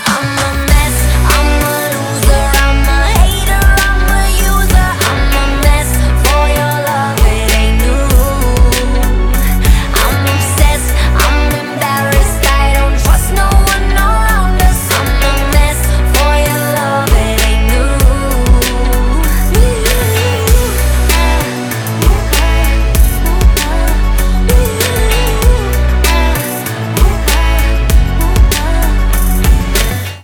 • Качество: 320, Stereo
ритмичные
громкие
заводные
dance
чувственные
RnB
красивый женский голос
Bass
озорные